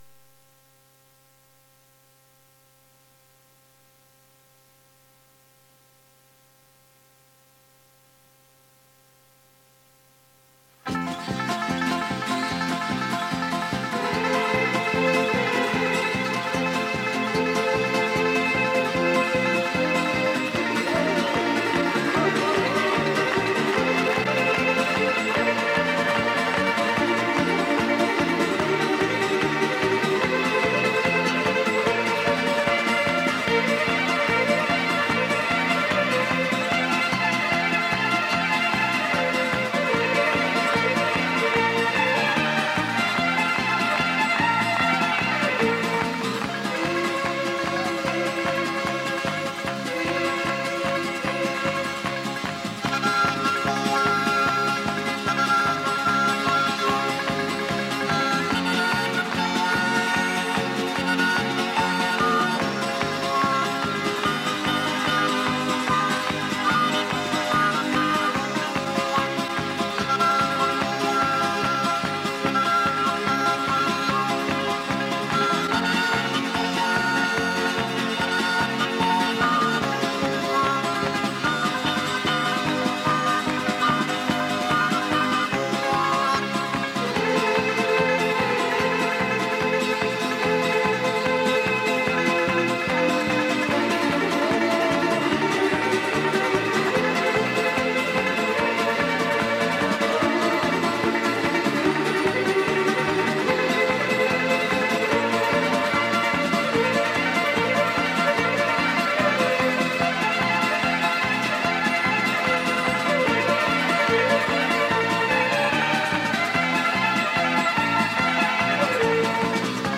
磁带数字化：2022-07-16
这是一盒描写美国西部的音乐卡带。